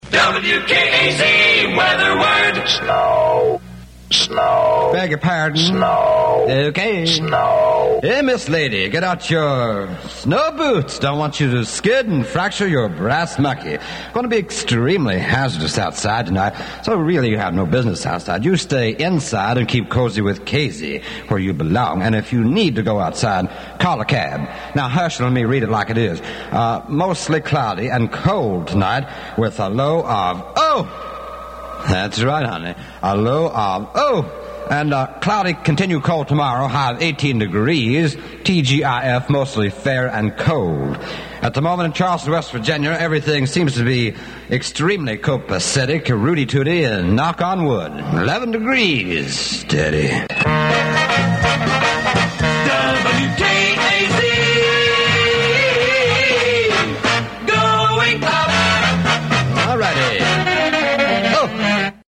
The Weather Report
weather_id.mp3